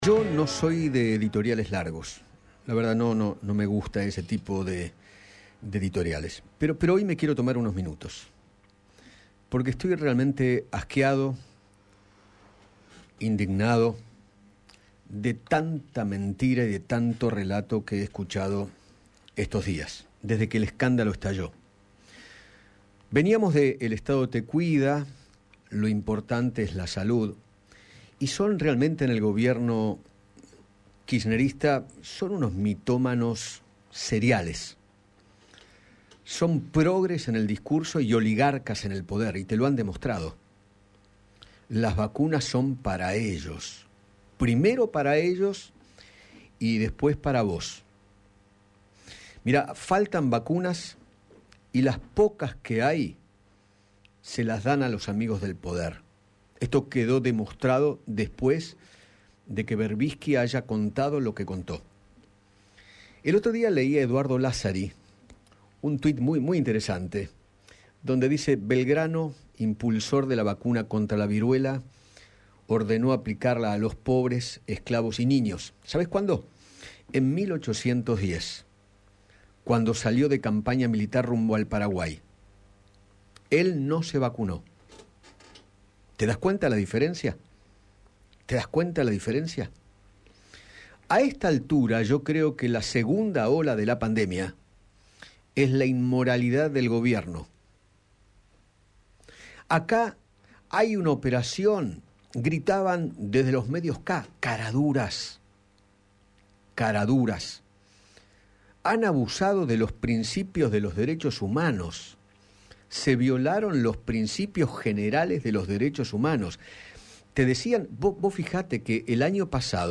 Eduardo Feinmann realizó esta mañana una nueva editorial acerca del escándalo del vacunatorio vip, que terminó con la renuncia de Ginés González García y la asunción de Carla Vizzotti como ministra de Salud.